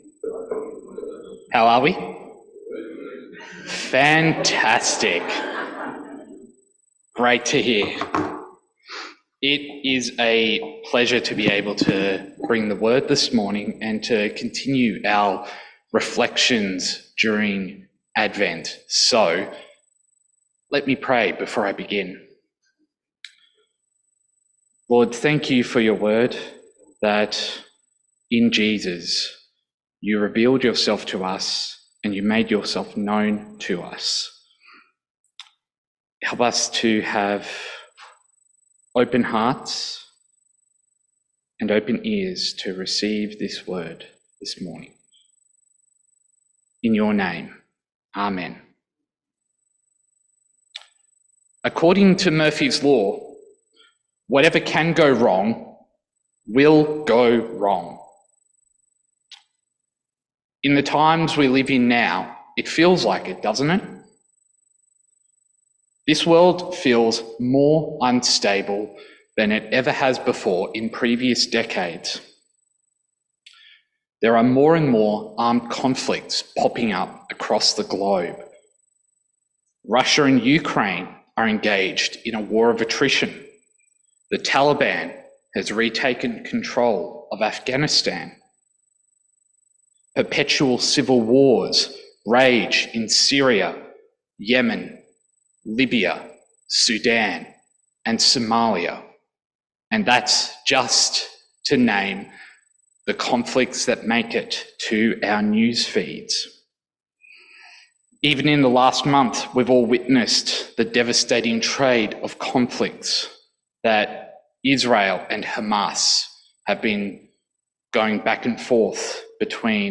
Peace on Earth Passage: Luke 1:26-38 Service Type: 10am Service « Advent 1